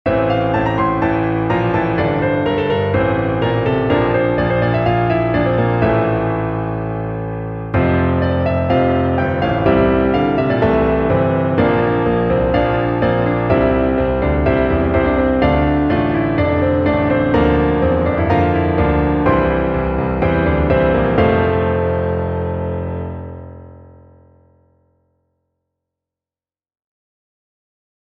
Starts in A phrygian and ends in D minor.
piano music